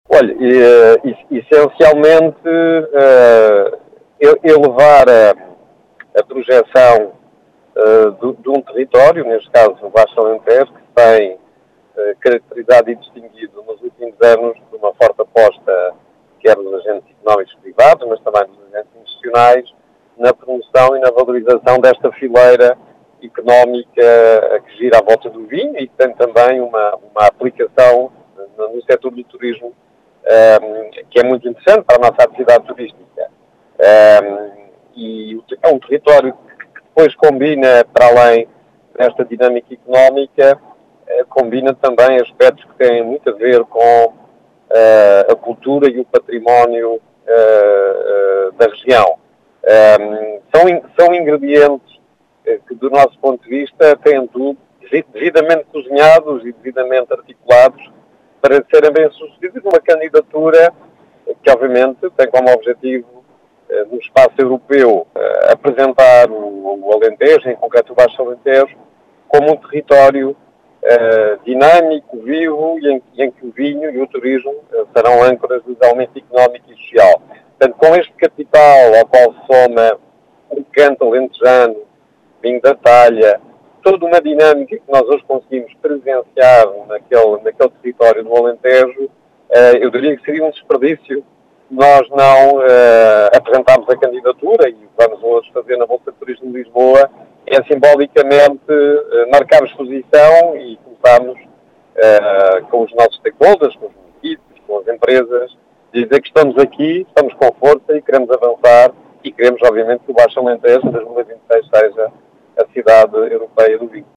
Em declarações à Rádio Vidigueira, o presidente da Entidade Regional de Turismo do Alentejo e Ribatejo, José Manuel Santos, diz ser o “elevar a projeção” do território, que com as potencialidades que apresenta, seria um “desperdício” não apresentar esta candidatura.